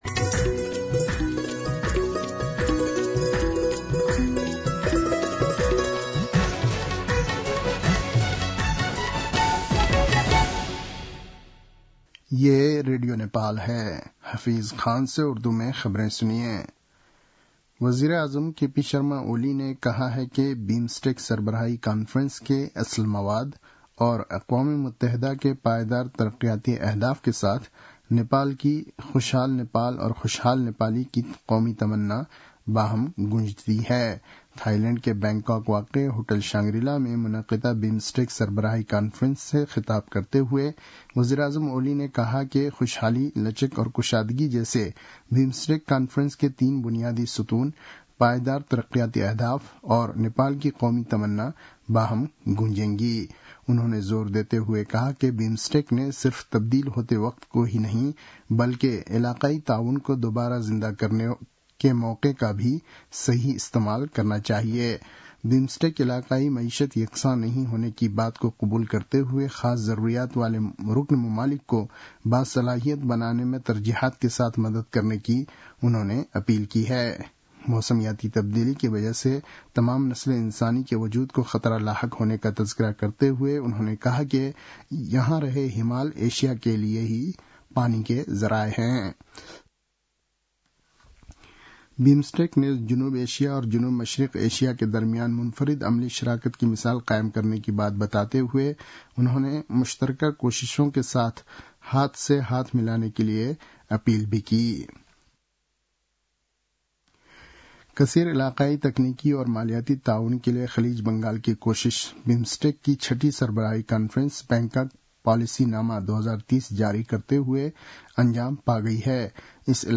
उर्दु भाषामा समाचार : २२ चैत , २०८१